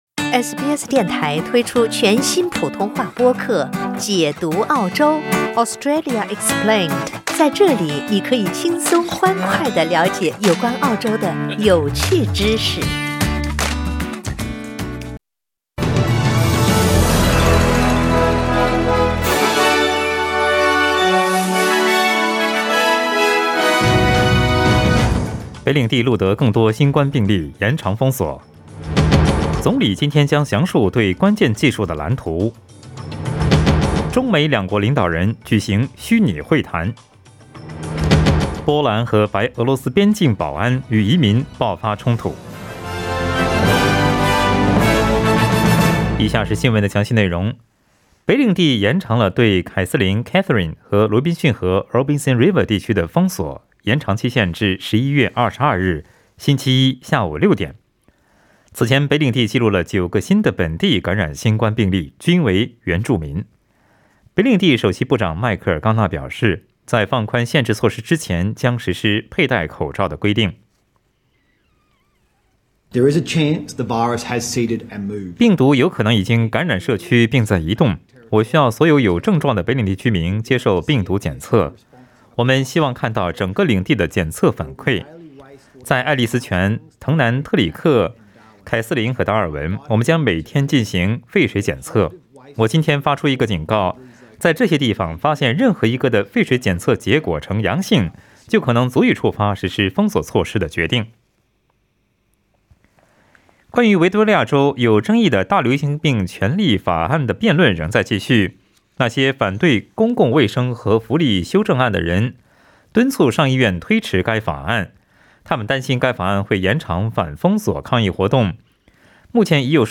SBS早新聞（11月17日）
SBS Mandarin morning news Source: Getty Images